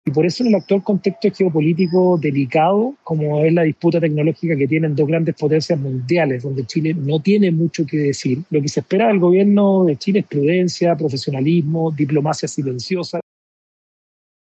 El senador Javier Macaya (UDI) llamó al próximo gobierno a actuar con especial cautela ante la pugna entre ambas potencias.